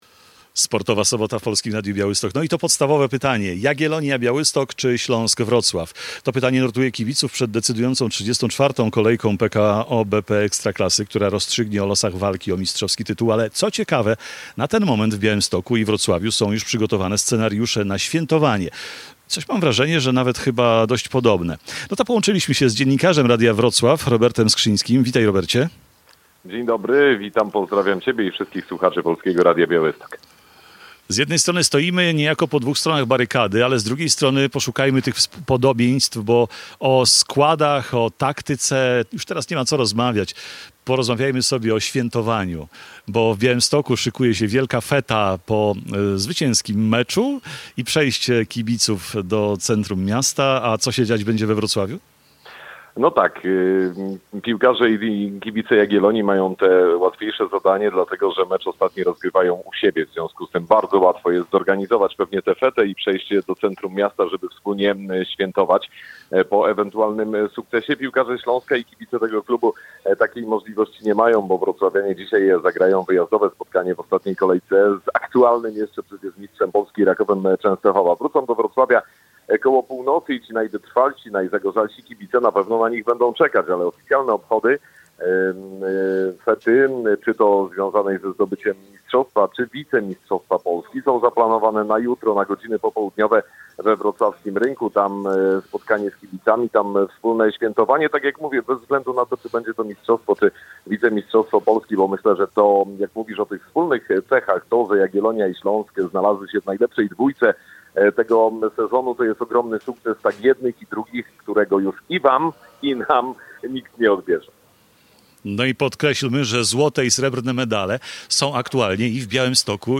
Nasz program rozpoczęliśmy o 14:00 z plenerowego studia na stadionie miejskim w Białymstoku.